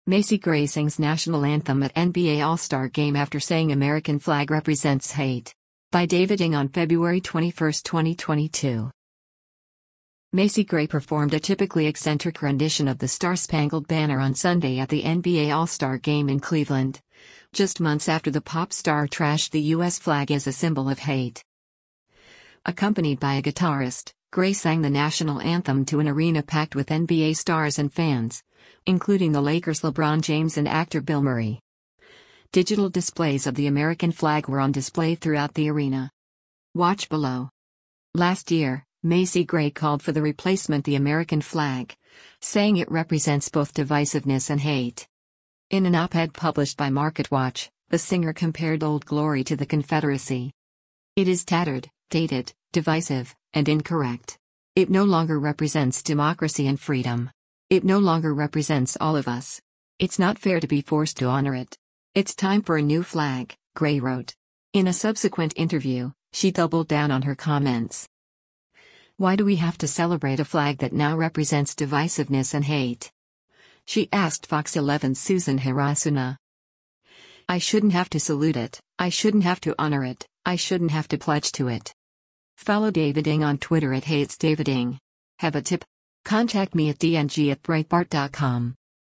Macy Gray performed a typically eccentric rendition of “The Star-Spangled Banner” on Sunday at the NBA All-Star Game in Cleveland, just months after the pop star trashed the U.S. flag as a symbol of “hate.”
Accompanied by a guitarist, Gray sang the national anthem to an arena packed with NBA stars and fans, including the Lakers’ LeBron James and actor Bill Murray.